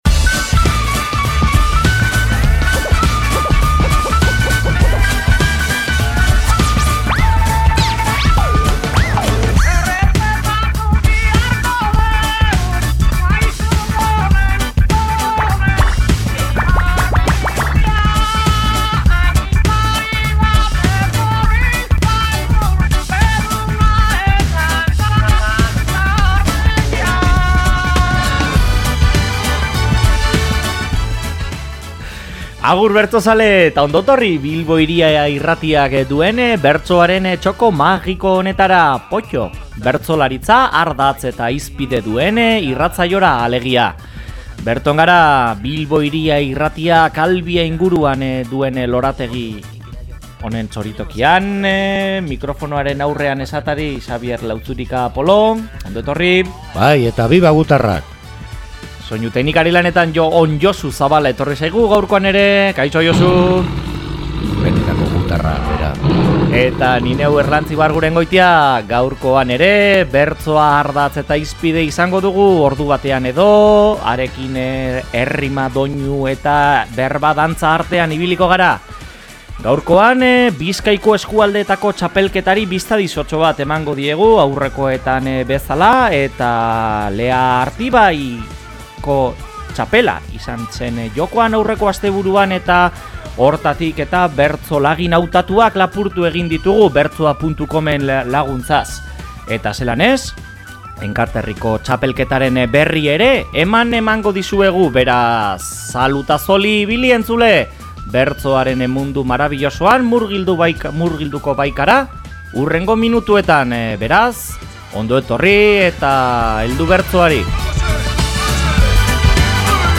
Goza ditzagun bertso musikatuak!.